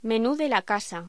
Locución: Menú de la casa
voz